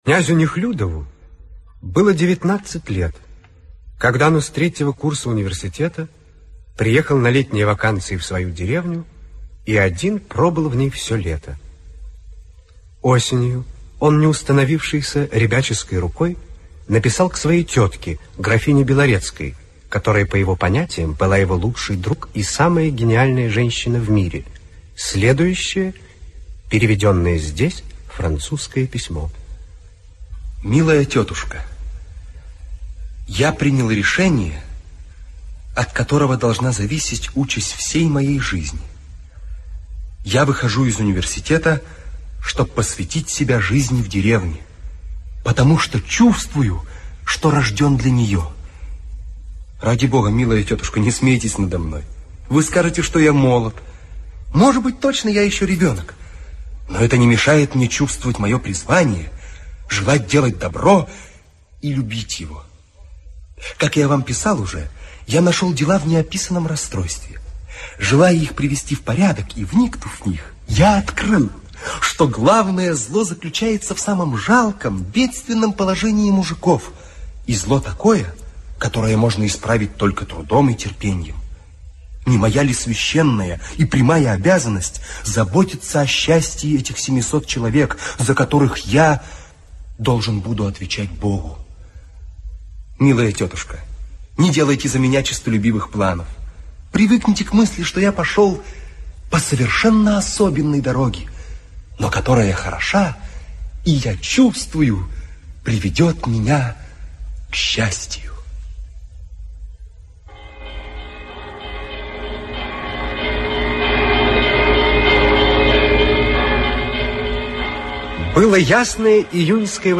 Аудиокнига Утро помещика (спектакль) | Библиотека аудиокниг